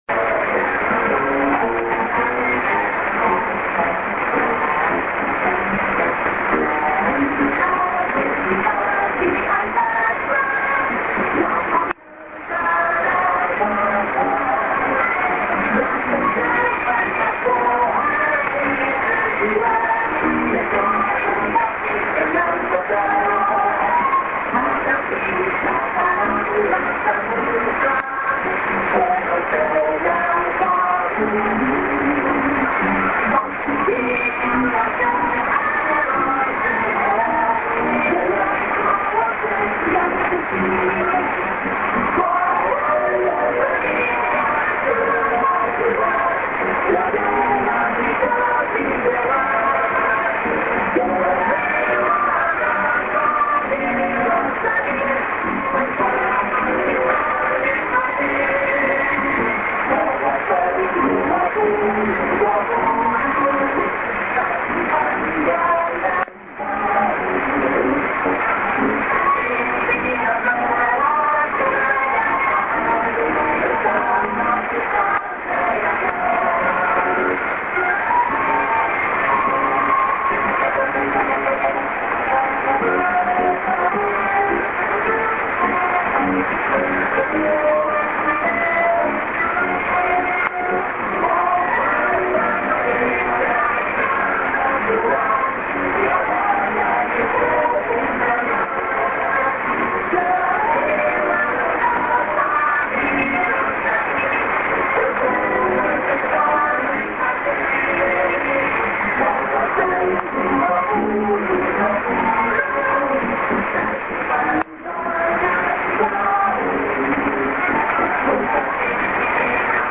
NA??->02'37":ID:RTM(man)->ID(women)->TS->>
ＷＲＴＨに載っているローカルＩＤは録れませんでした。